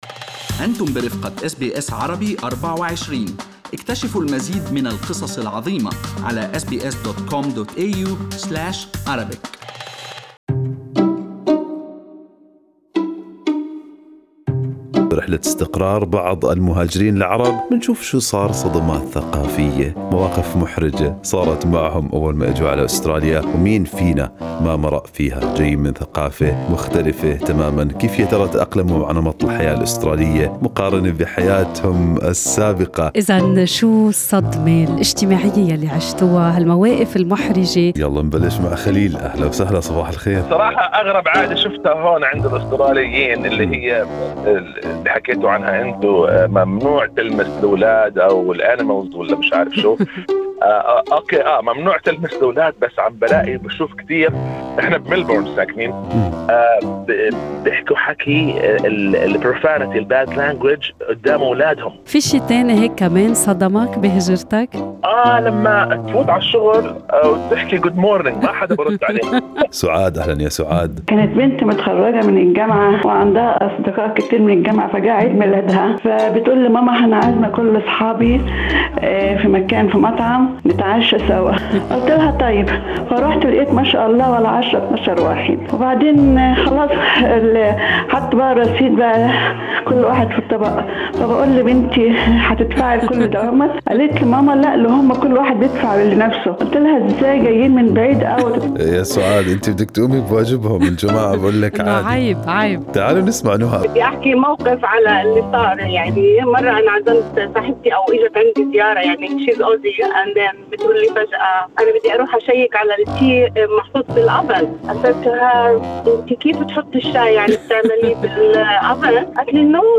برنامج Good Morning Australia طرح هذا السؤال على المستمعين والمستمعات في فقرة الحوار المباشر، لتأتي المشاركات متنوعة وكل منها يضيء على زاوية خاصة من الحياة الجديدة.